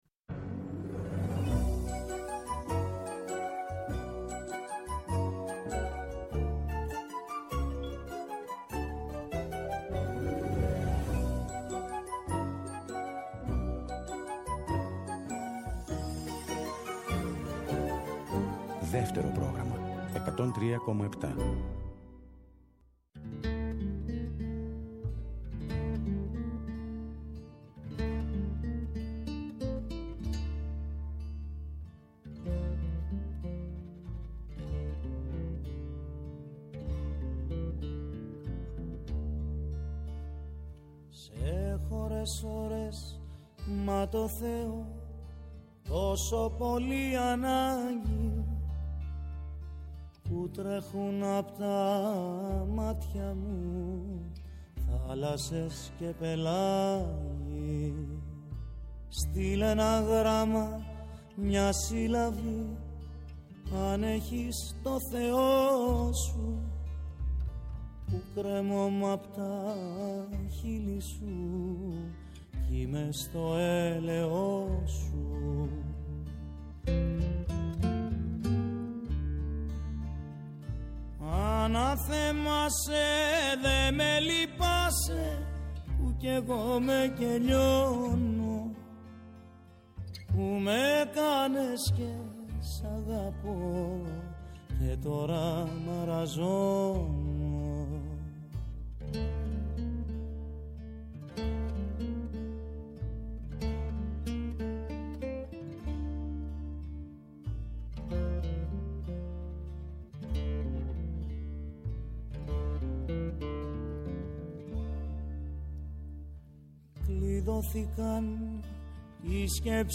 Οι Πλανόδιες Μουσικές φιλοξενούν τον Παντελή Θαλασσινό, που μετρά τρείς δεκαετίες προσωπικής πορείας στο τραγούδι.